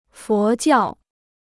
佛教 (fó jiào) Free Chinese Dictionary